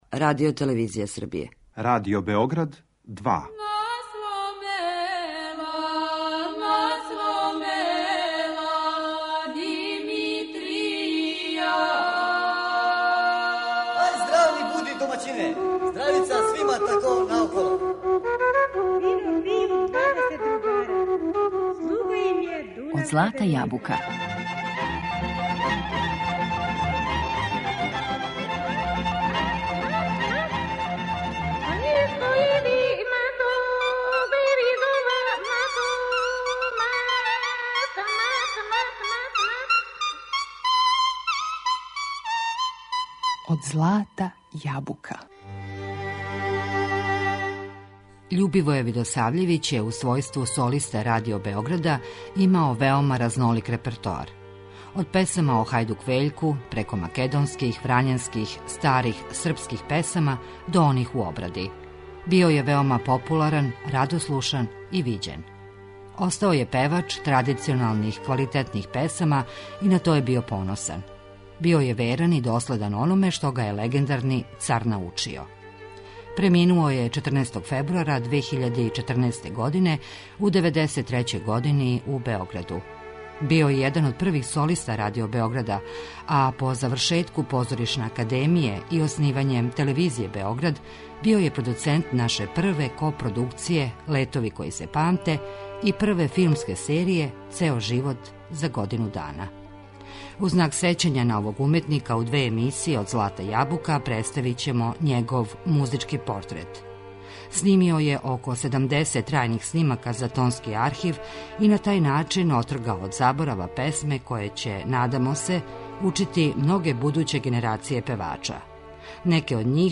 од песама о хајдук Вељку, преко македонских, врањанских, до оних у обради. Упамћен је као певач традиционалних, квалитетних песама, био је веран и доследан ономе што га је учио Властимир Павловић Царевац.